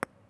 Added ball sfx
concrete6.wav